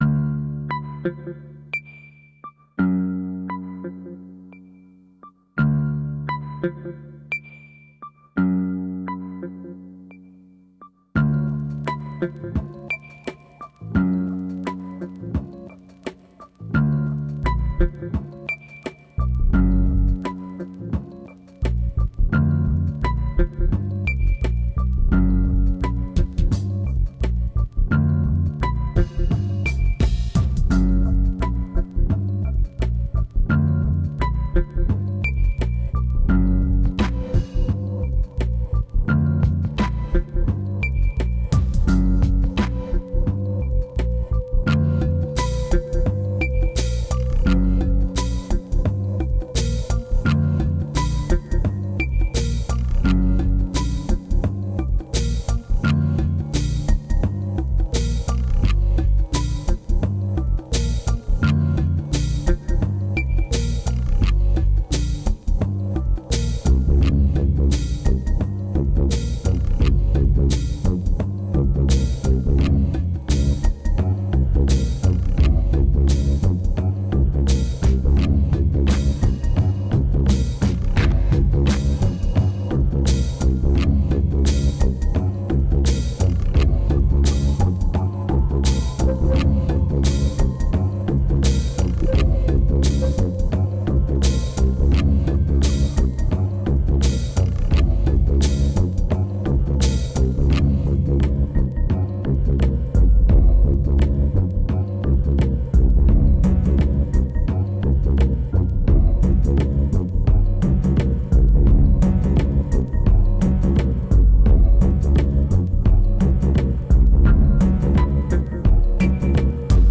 2344📈 - -34%🤔 - 86BPM🔊 - 2010-07-04📅 - -536🌟